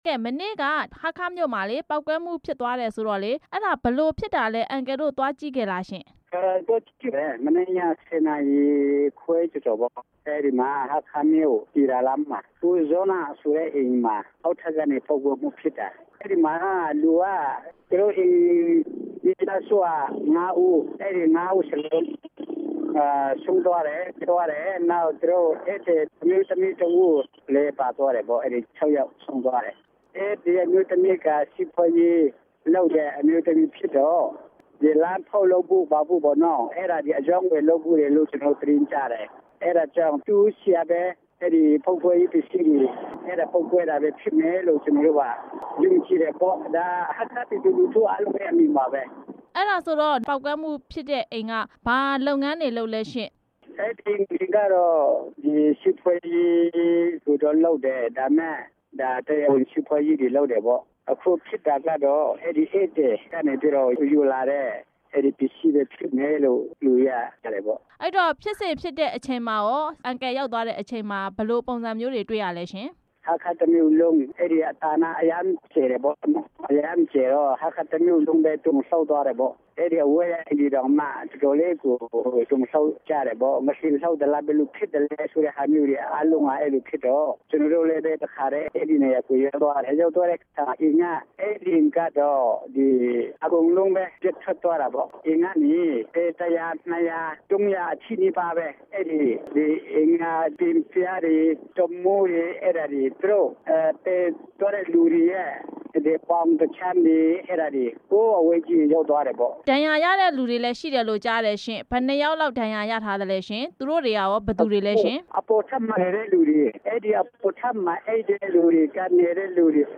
ဟားခါးက ယမ်းပေါက်ကွဲမှု၊ ၆ ဦး သေဆုံးတဲ့အကြောင်း မေးမြန်း ချက်